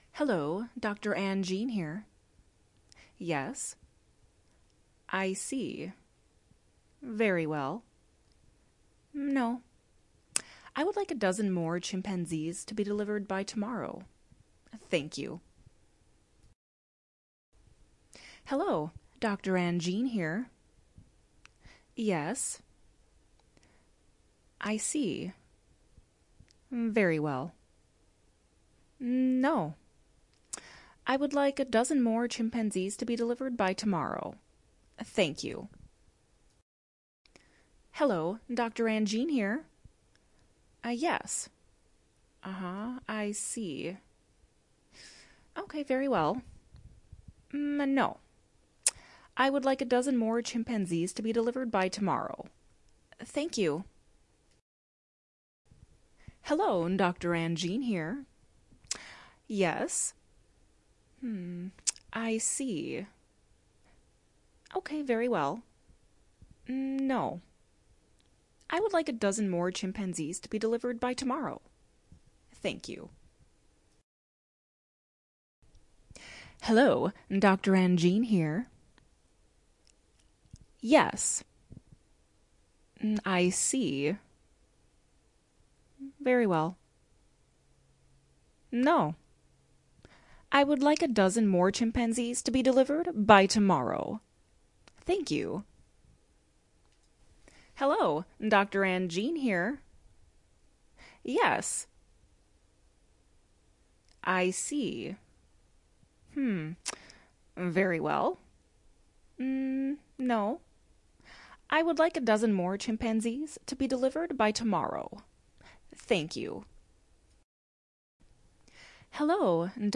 录制了USB麦克风和Audacity。
Tag: 讲话 谈话 声音 女孩 美国 声乐 请求女人